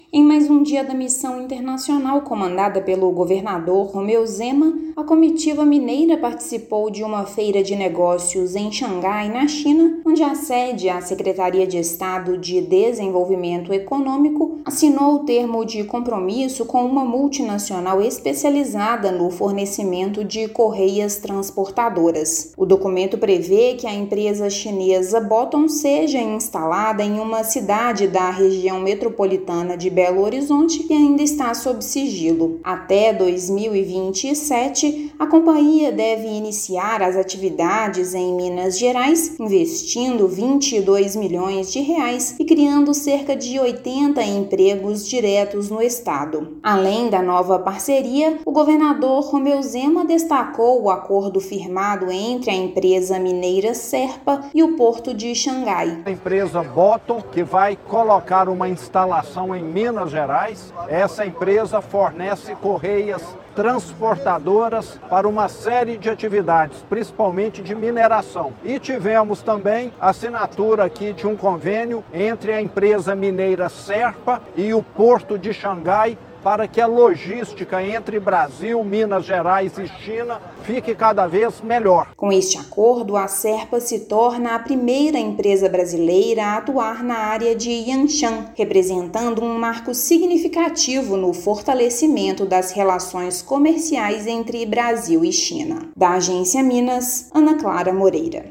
[RÁDIO] Com apoio do Governo de Minas, multinacional chinesa anuncia investimento de R$ 22 milhões para iniciar operações na RMBH
Nova unidade da Wuxi Boton criará cerca de 80 empregos diretos em Minas Gerais até 2027, impulsionando a sustentabilidade no setor de mineração. Ouça matéria de rádio.